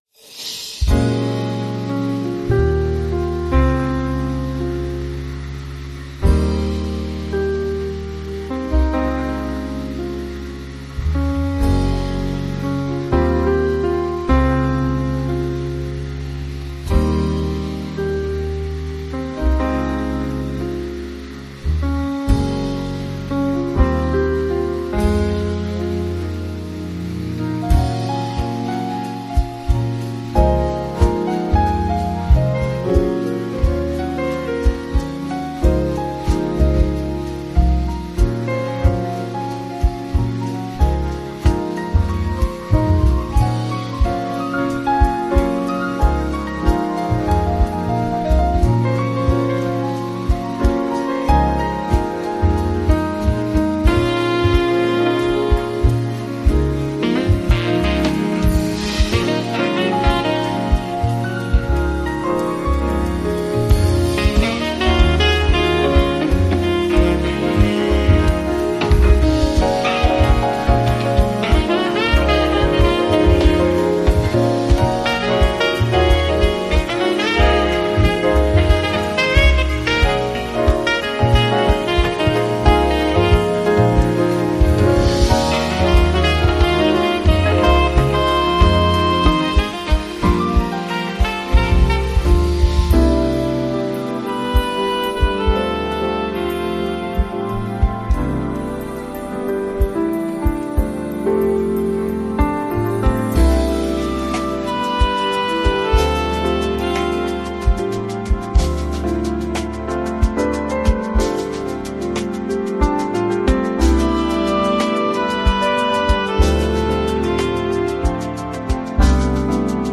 ・アウトロでは、音楽が徐々にフェードアウトし、再びピアノがメロディーを引き継ぎます。